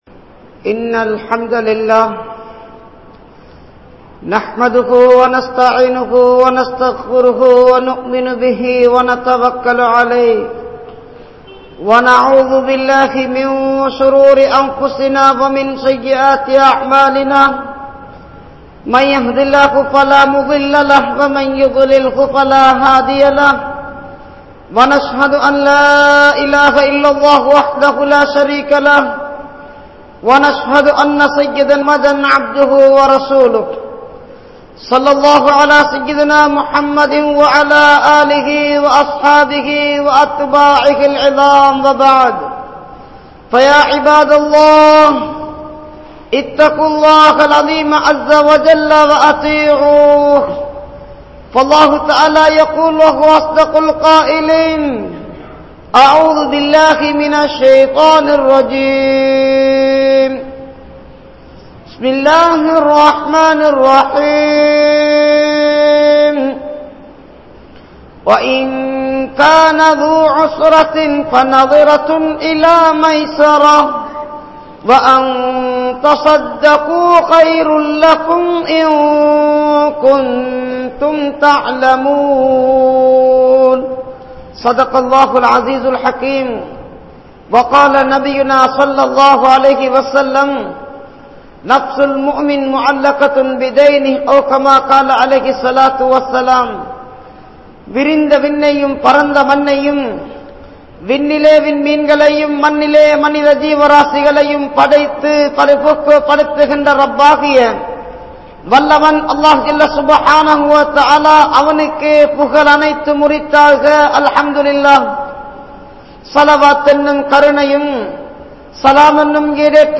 Muamalath And Muasharath | Audio Bayans | All Ceylon Muslim Youth Community | Addalaichenai
Gorakana Jumuah Masjith